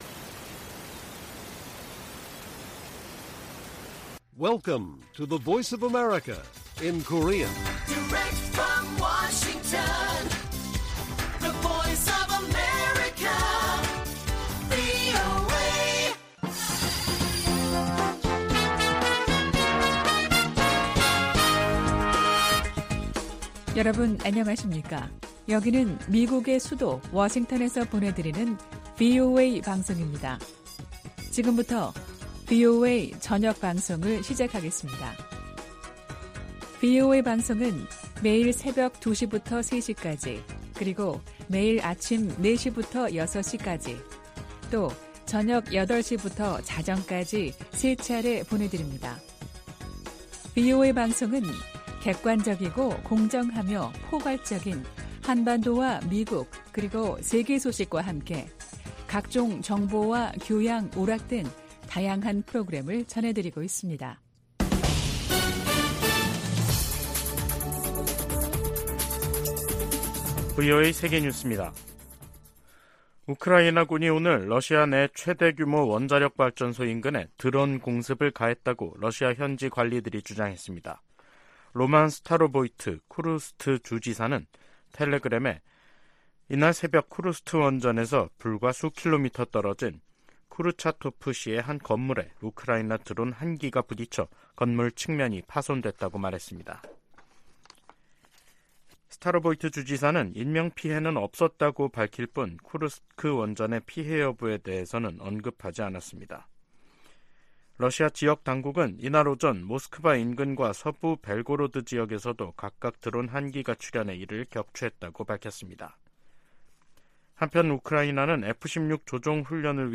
VOA 한국어 간판 뉴스 프로그램 '뉴스 투데이', 2023년 9월 1일 1부 방송입니다. 미국 재무부가 북한의 대량살상무기(WMD)와 탄도미사일 개발에 자금조달을 해온 북한인과 러시아인들을 제재했습니다. 미 의회 산하 중국위원회가 유엔 인권과 난민 기구에 서한을 보내 중국 내 탈북민 북송을 막도록 개입을 요청했습니다. 미 국방부가 북한에 러시아와의 무기 거래 협상 중단을 촉구했습니다.